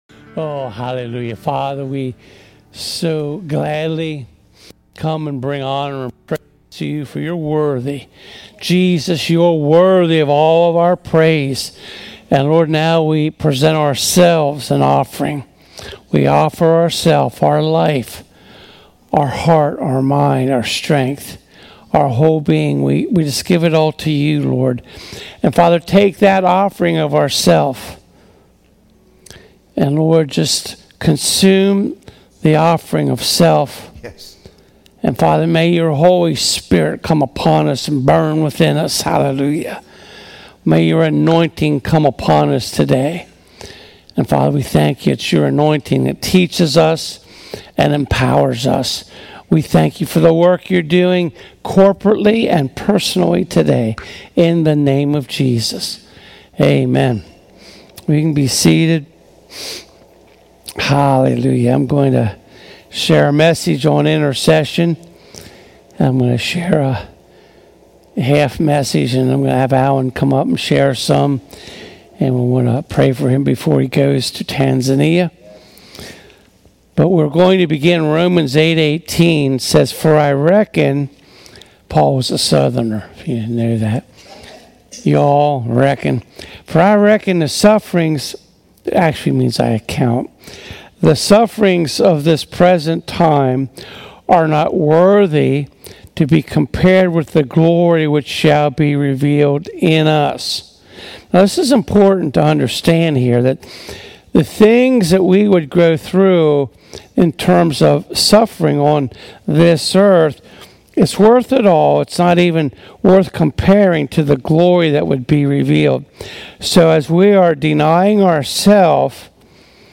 Preaching Service